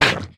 Minecraft Version Minecraft Version latest Latest Release | Latest Snapshot latest / assets / minecraft / sounds / entity / squid / hurt3.ogg Compare With Compare With Latest Release | Latest Snapshot
hurt3.ogg